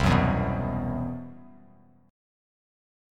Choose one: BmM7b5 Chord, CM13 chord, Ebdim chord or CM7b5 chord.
CM7b5 chord